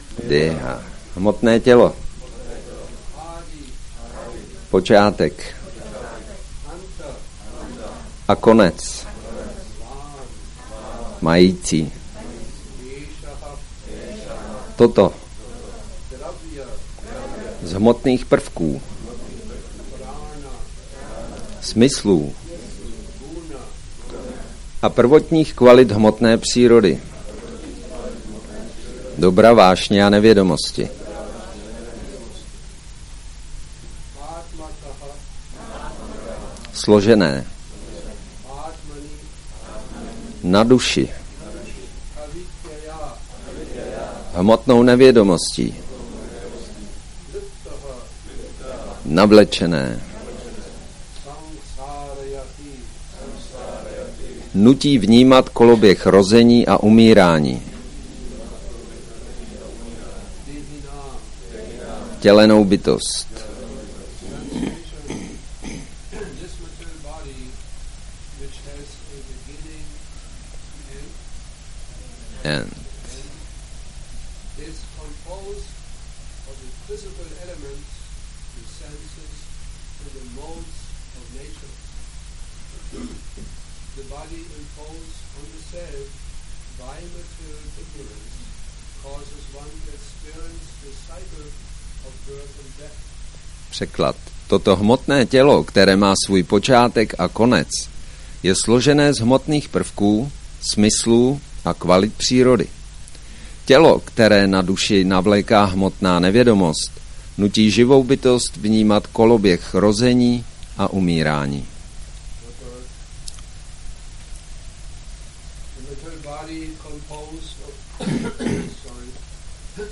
Přednáška SB-10.54.45 – Šrí Šrí Nitái Navadvípačandra mandir